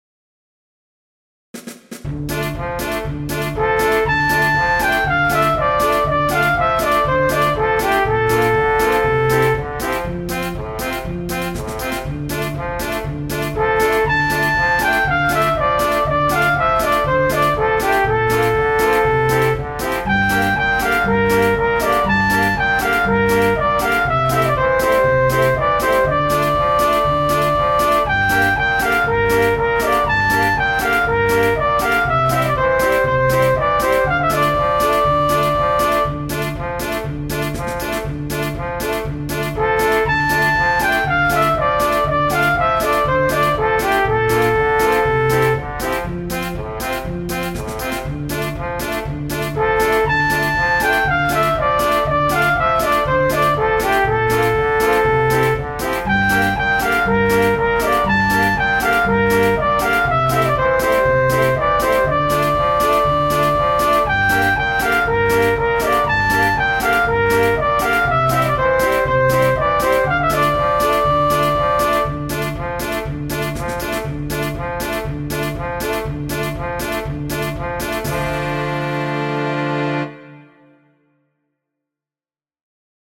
Adaptation rythmée pour OAE